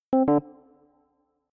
LeftCall.mp3